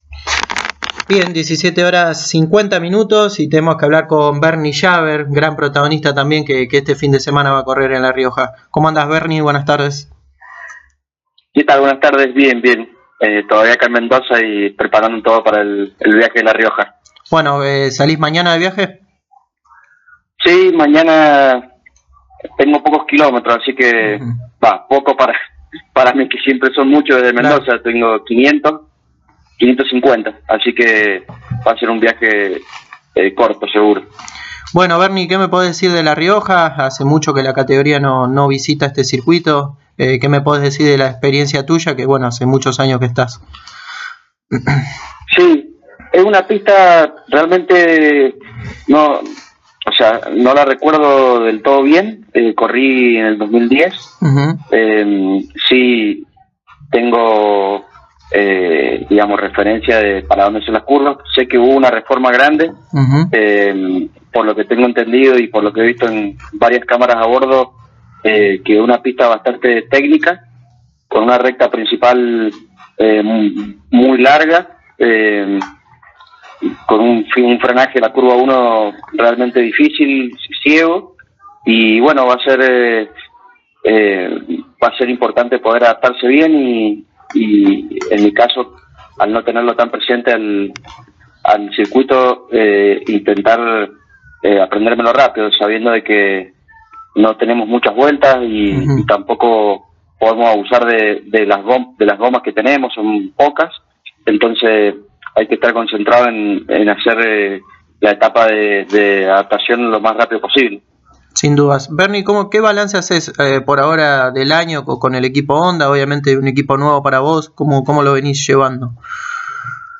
El mendocino pasó por los micrófonos de Pole Position y habló de como se prepara para un nuevo fin de semana con el equipo Honda de TC2000 en La Rioja. Además habló de la prueba realizada con el equipo TTA de TCR South America en Villicum, el martes h miércoles.